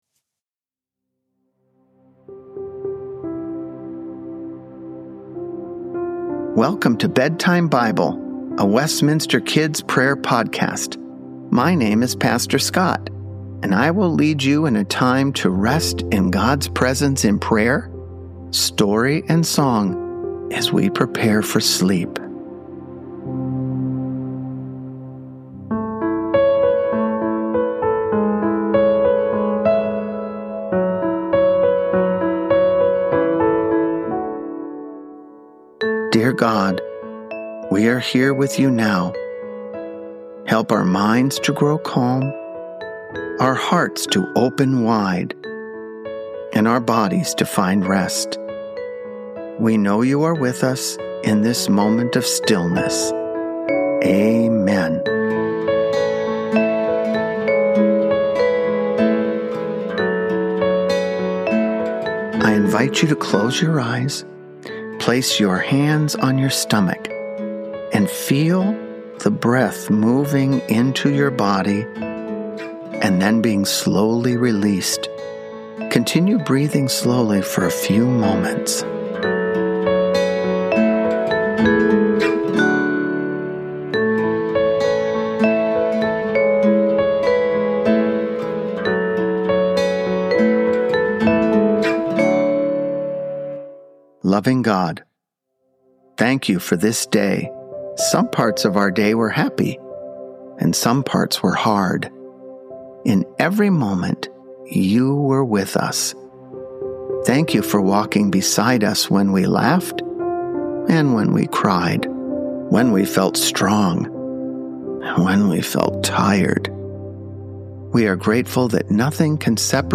Evening Song - "Come Thou Fount of Every Blessing" - words by Robert Robinson (1758).
Each podcast will feature calming music, Scripture and prayers to help children unwind from their day.